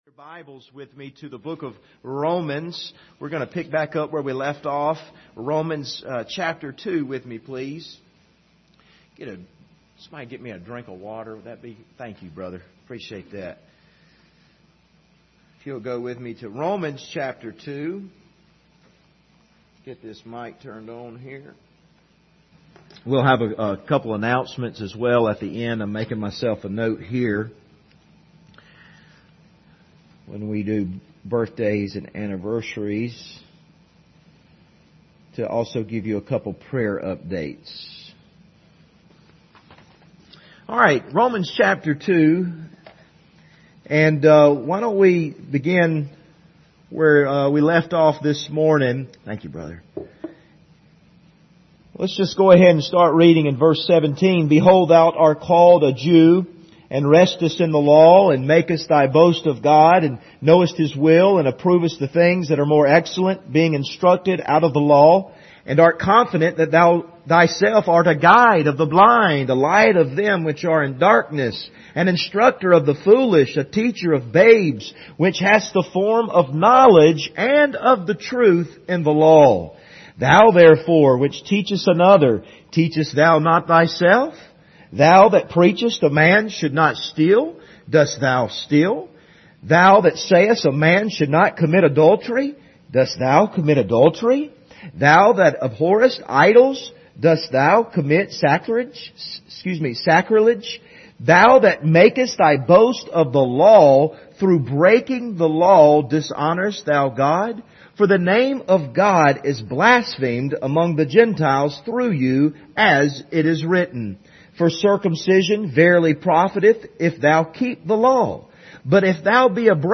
Passage: Romans 2:17-29 Service Type: Sunday Evening